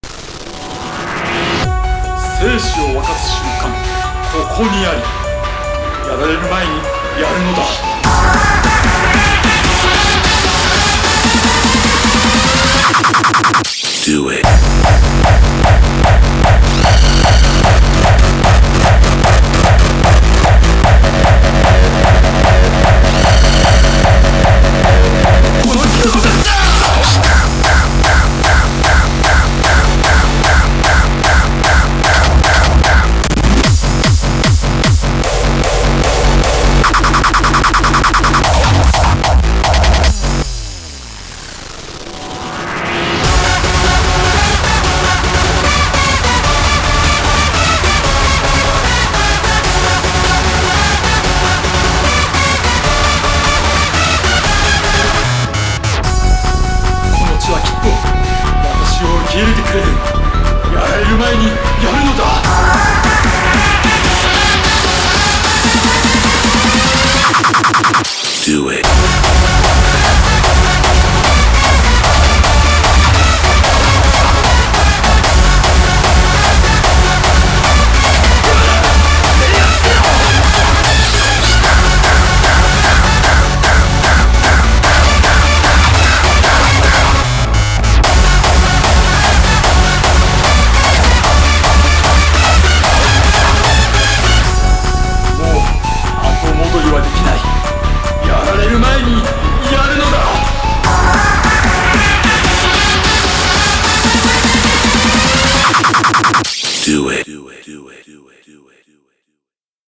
BPM150
Audio QualityPerfect (High Quality)
A pretty banger Rawstyle.